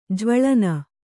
♪ jvaḷana